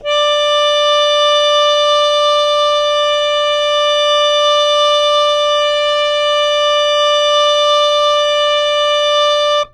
harmonium
D5.wav